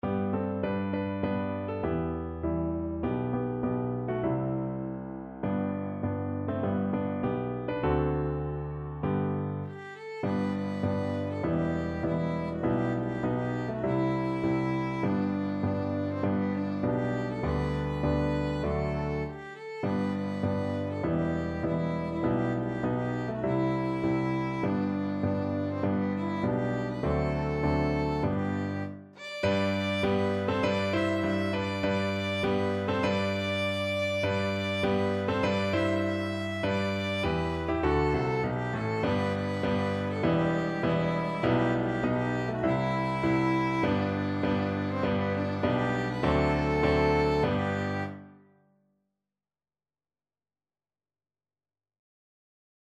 Moderato
4/4 (View more 4/4 Music)
Traditional (View more Traditional Violin Music)